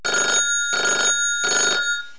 klingeln.wav